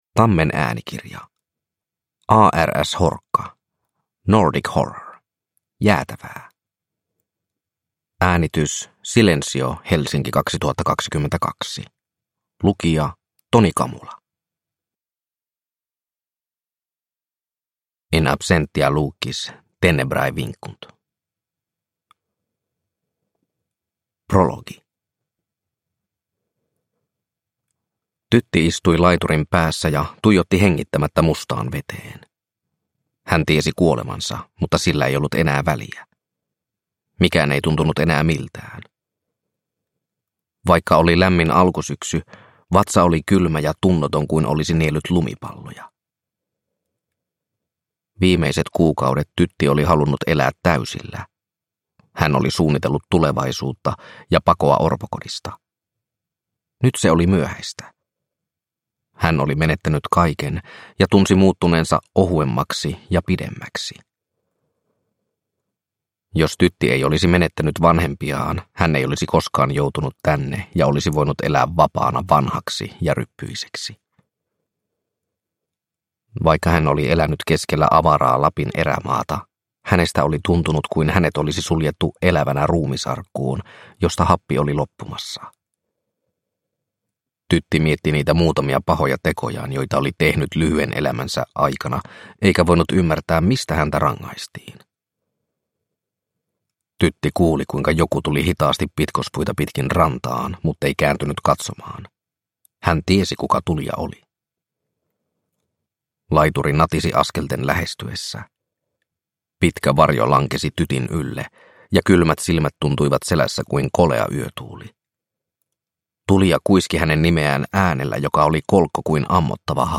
Jäätävää – Ljudbok – Laddas ner